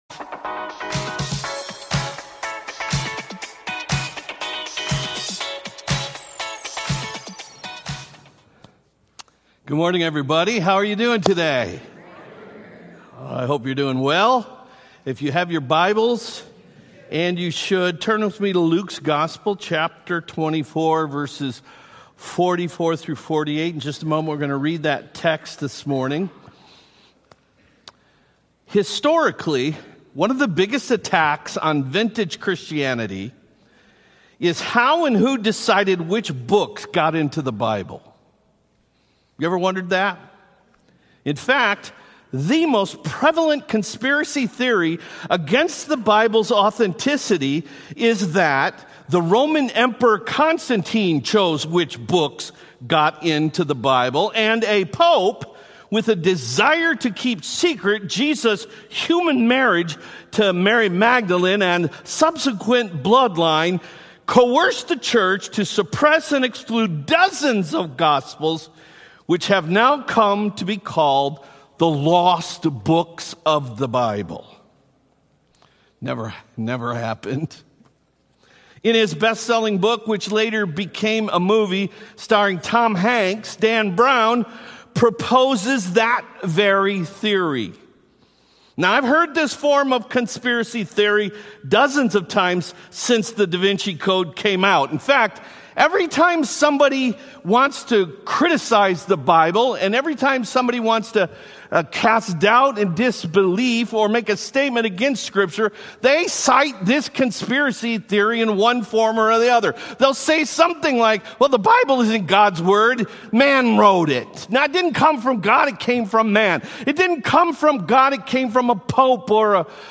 Not Negotiable Sermon Series – The Authority of the Bible of the Christian Faith is not negotiable The Bible’s Authority...